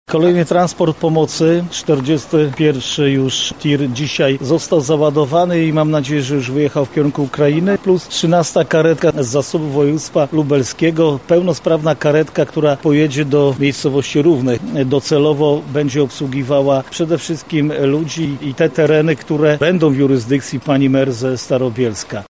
Jarosław Stawiarski– mówi marszałek województwa lubelskiego Jarosław Stawiarski